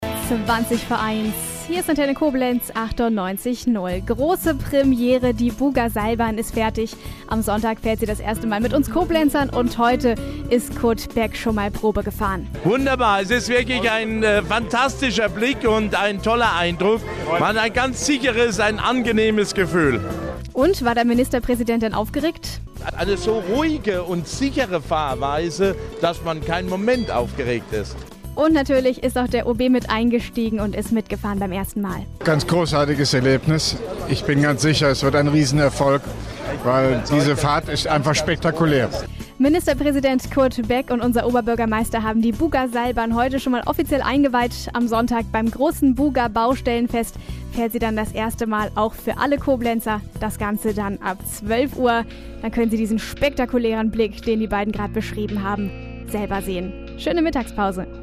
Mit Statements des rheinland-pfälzischen Ministerpräsidenten Kurt Beck und dem Koblenzer OB Hofmann-Göttig